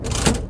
lever4.ogg